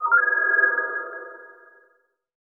End Call1.wav